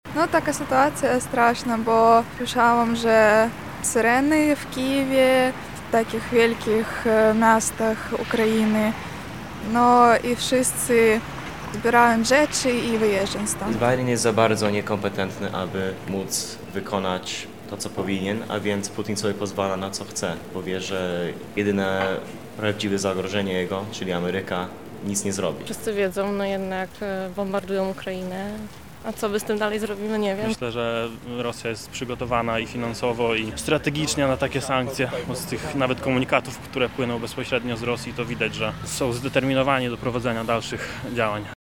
[SONDA] Co mieszkańcy Lublina myślą na temat sytuacji na Ukrainie?
Sonda